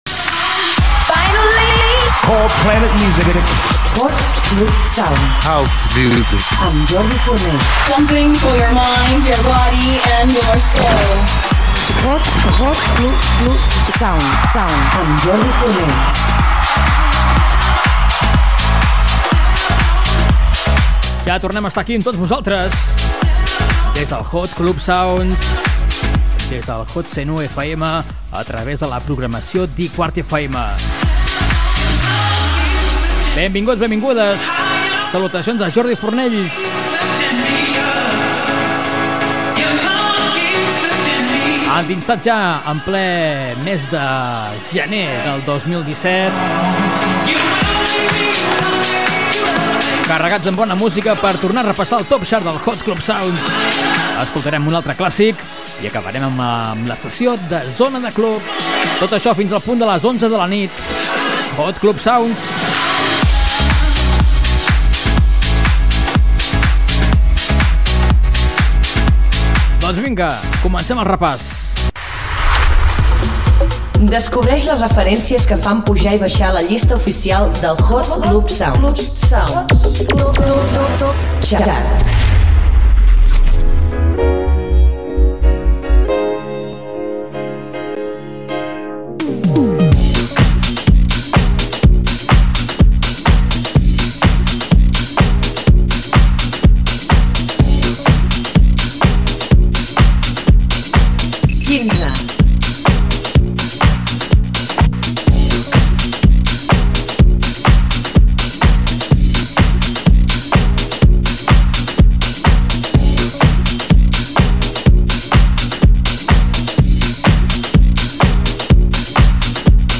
Careta del programa, presentació, indicatiu de la llista d'èxits, tema en la posició 15.
Musical